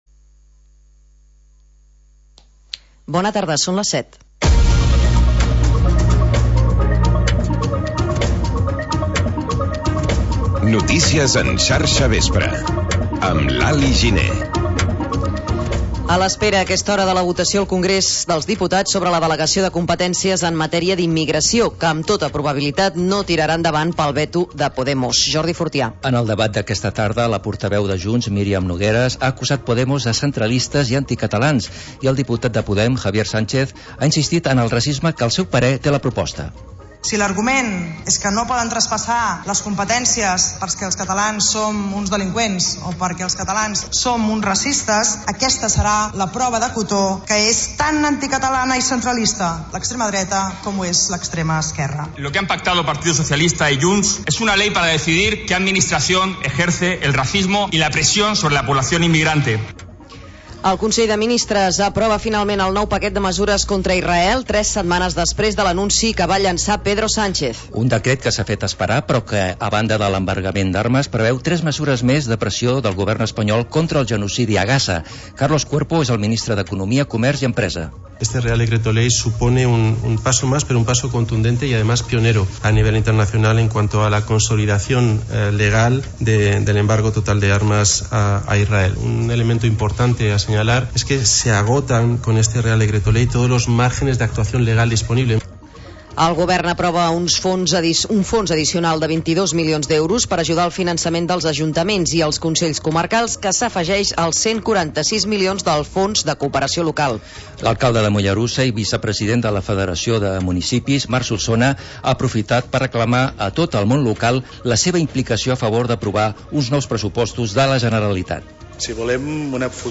Informatiu territorial